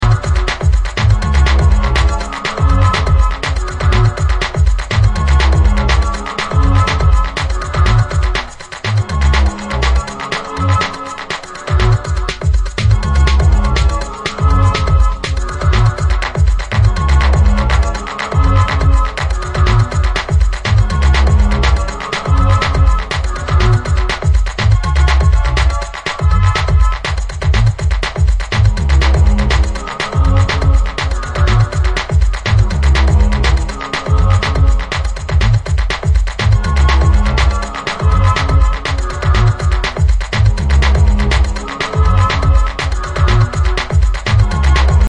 Techno Detroit